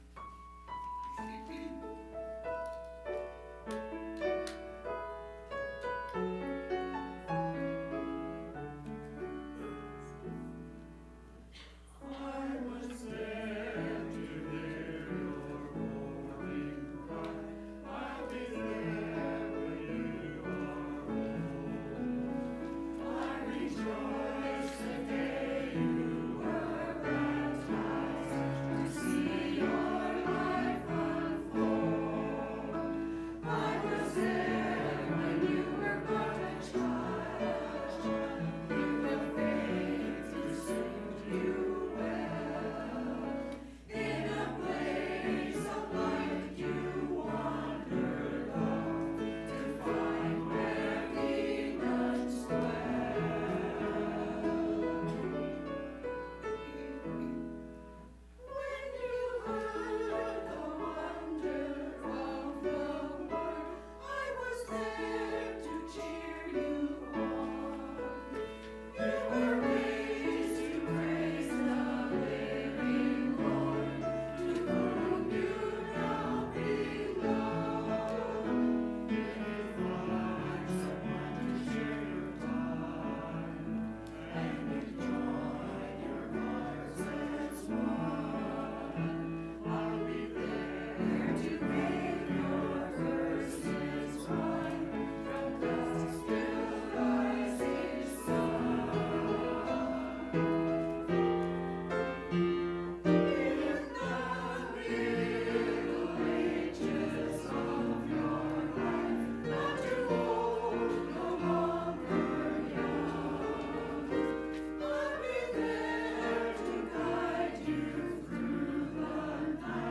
Church Choir – Borning Cry 10.13.19
To hear the church choir praise God with music please click play below.